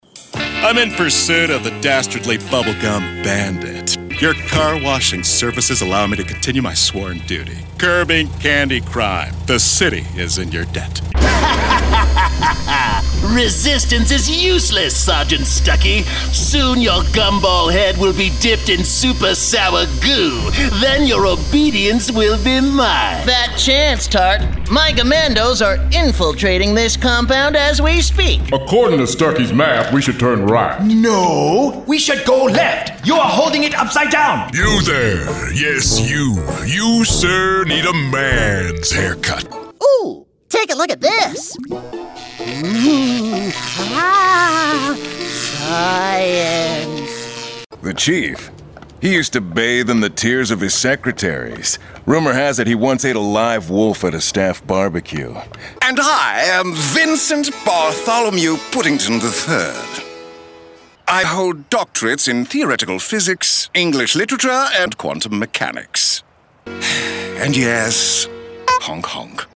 Animation
Professional Studio